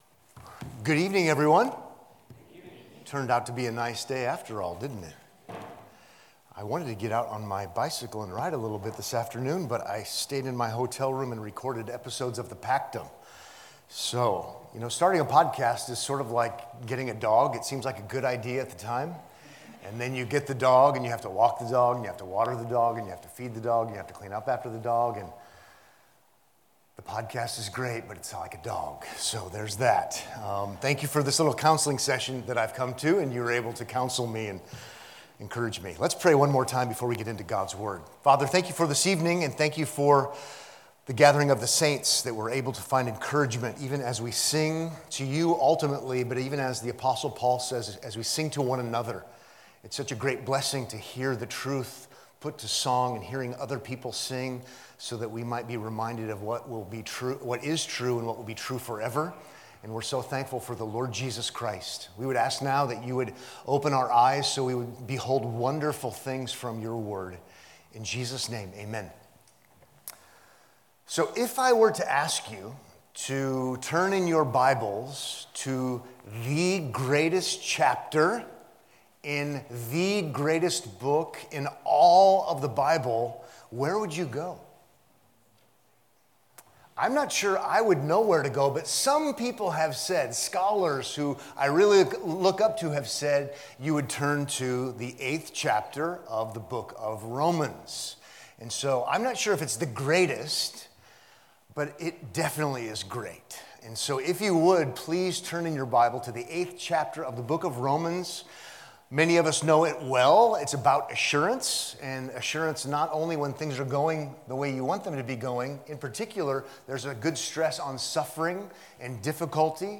No Condemnation Now – Spring Bible Conference Day 3